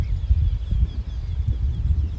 Tītiņš, Jynx torquilla
StatussDzirdēta balss, saucieni